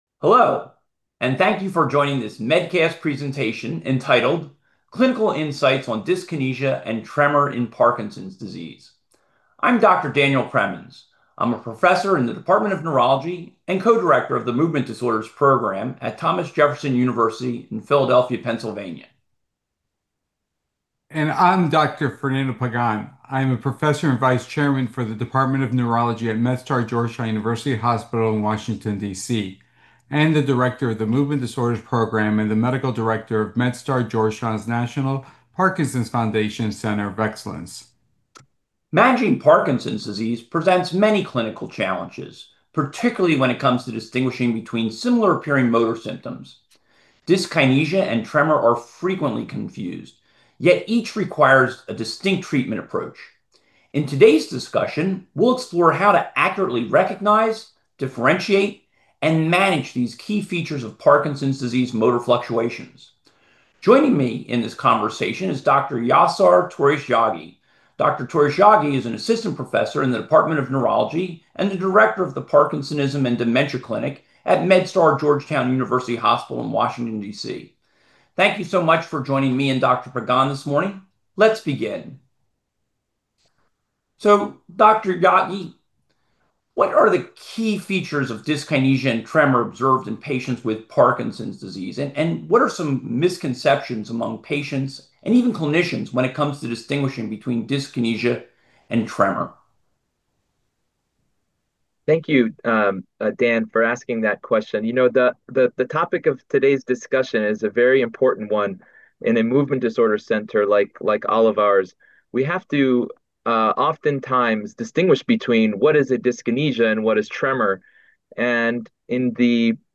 Panelist discusses how distinguishing between dyskinesia (choreoform, dance-like movements) and tremor in Parkinson's disease is crucial for treatment decisions, as modern therapeutic options including extended-release amantadine, continuous subcutaneous delivery systems, and advanced formulations can now effectively manage both motor fluctuations and troublesome dyskinesias simultaneously.